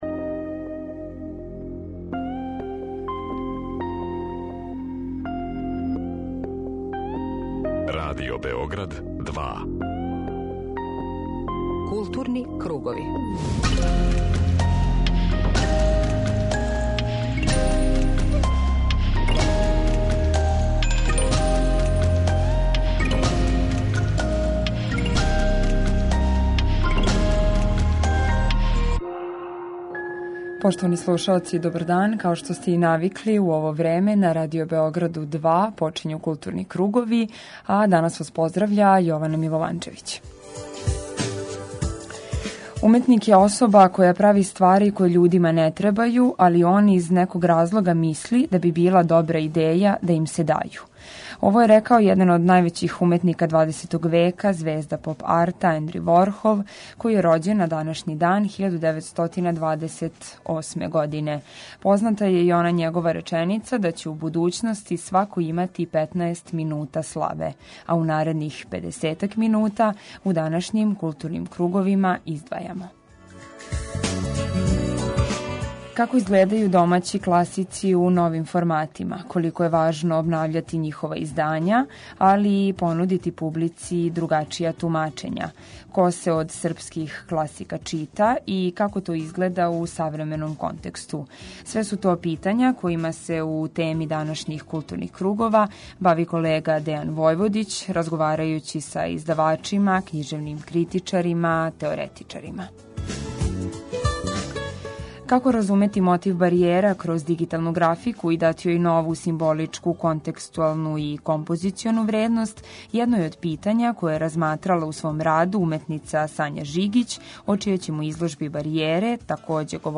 Дневни магазин културе
У теми данашњих Културних кругова говорићемо о класицима српске књижевности. Колико је важно обнављати тумачења, али и издања неких од најважнијих књижевних дела из историје српске књижевности, разговарали смо са издавачима, књижевним критичарима и другим књижевним делатницима.